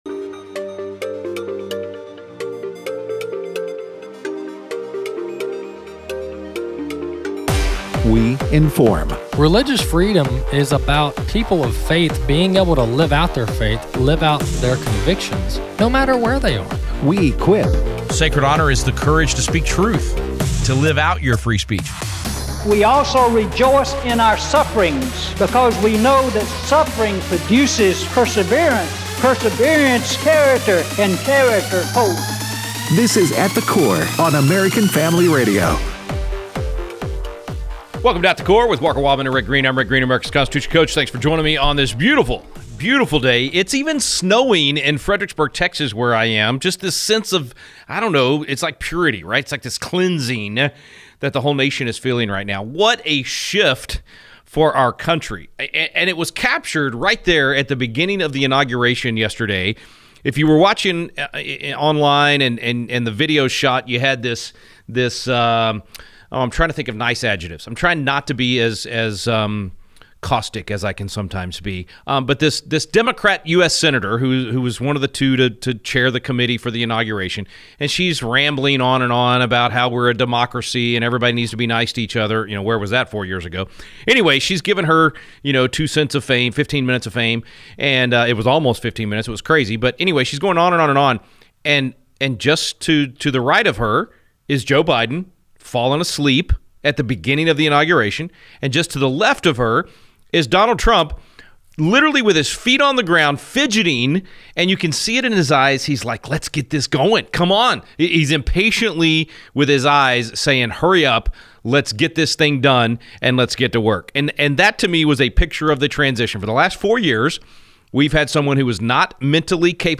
Callers share their thoughts on the inauguration yesterday